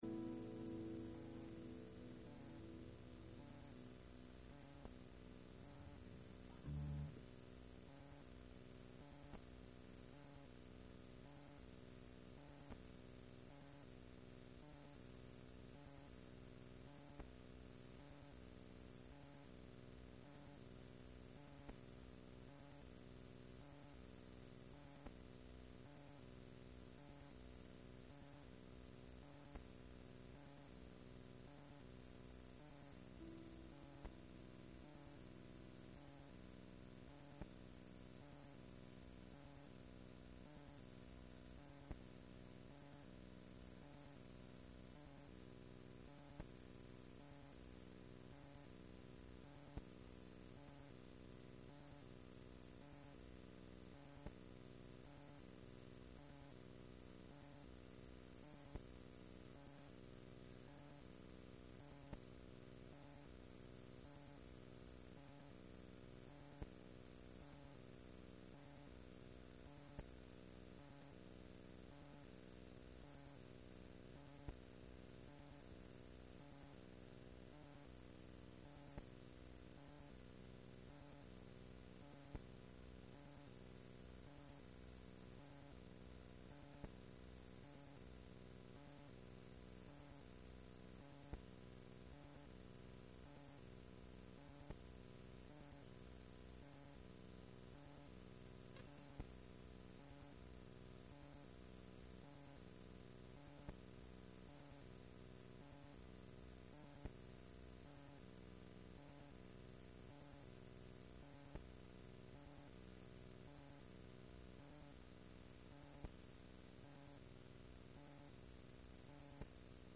God's plan for your life Service Type: Sunday Morning %todo_render% « God and your direction Back to Basics